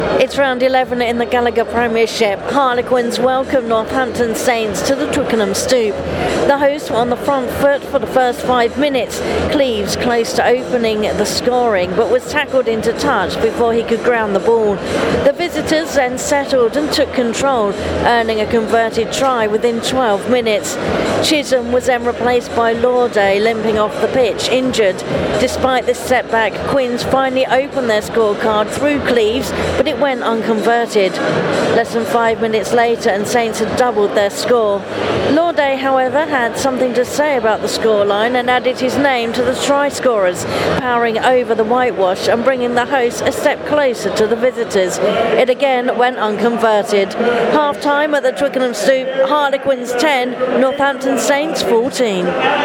The half-time update:
Half-Time at the Twickenham Stoop: Harlequins 10 – 14 Northampton Saints